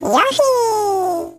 Voice clip from Mario & Sonic at the London 2012 Olympic Games